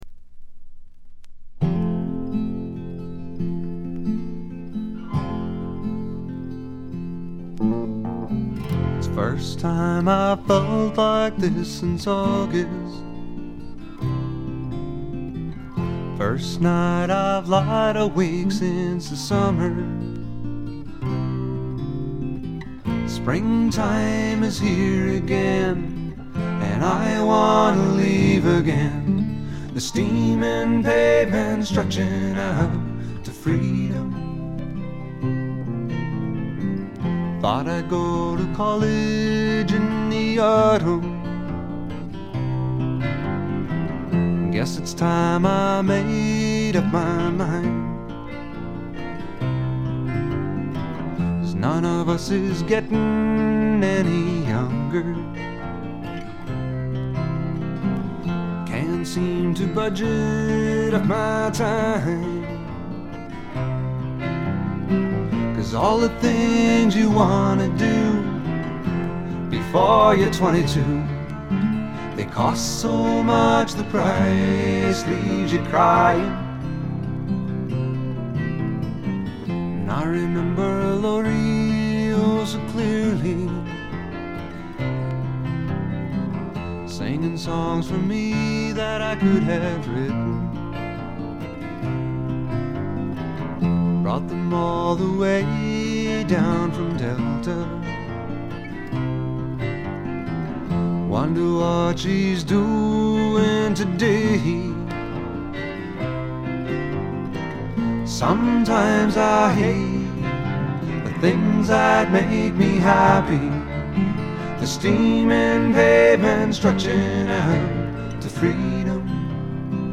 軽微なチリプチほんの少し。
試聴曲は現品からの取り込み音源です。